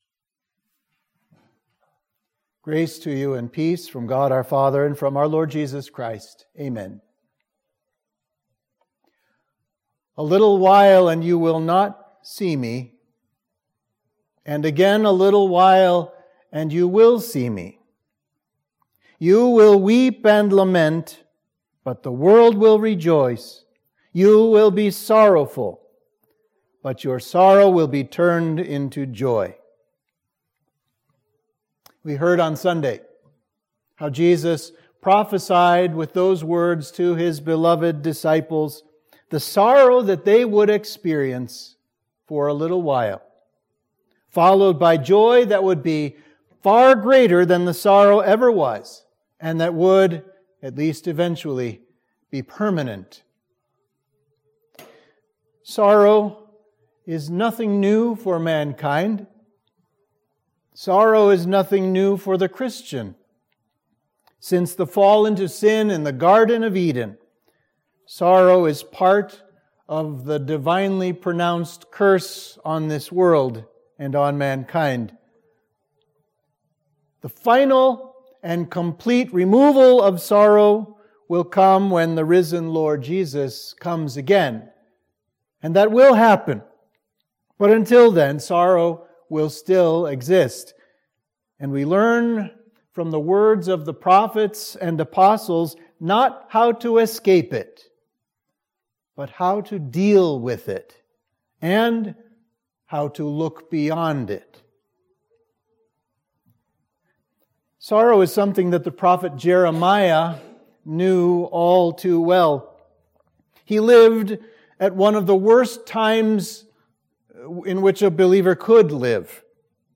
Sermon for Midweek of Easter 3